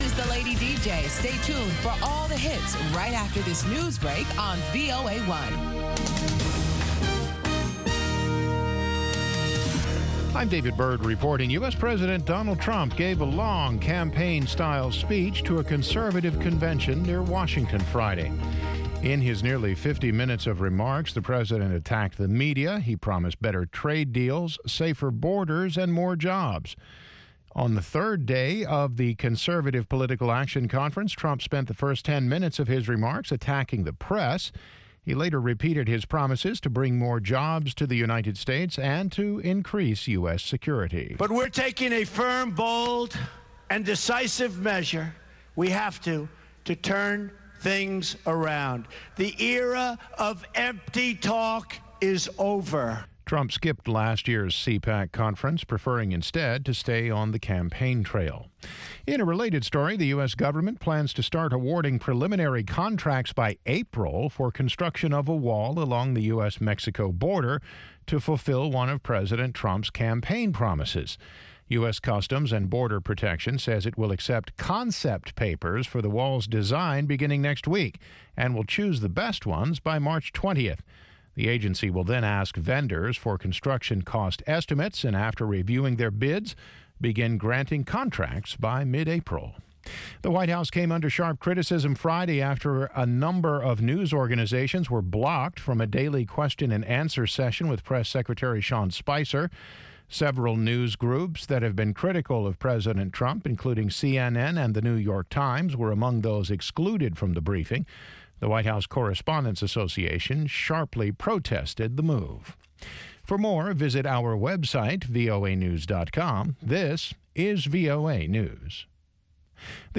Una discusión de 30 minutos sobre los temas noticiosos de la semana con diplomáticos, funcionarios de gobiernos y expertos.